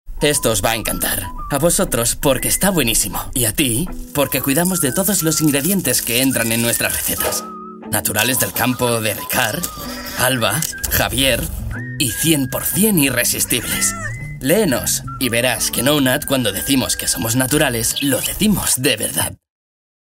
sehr variabel
Jung (18-30)
Eigene Sprecherkabine
Commercial (Werbung)